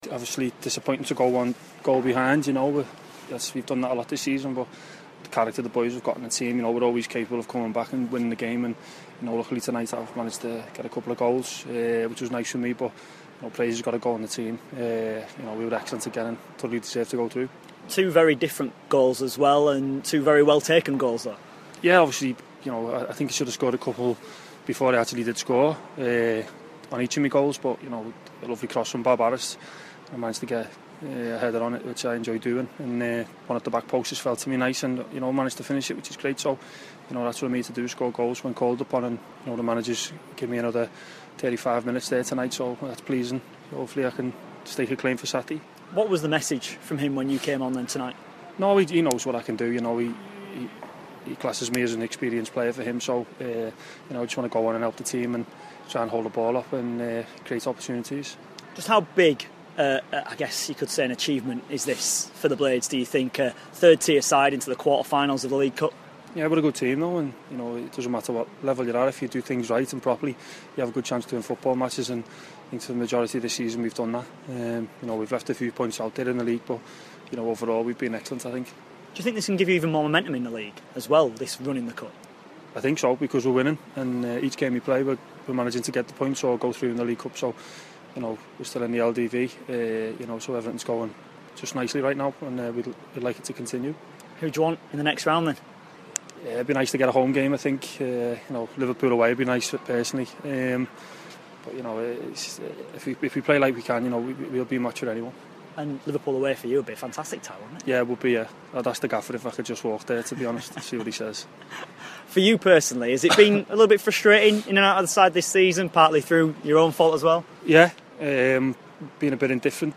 INTERVIEW: Sheffield United striker Michael Higdon after scoring a brace to take the blades into the League Cup Quarter Finals.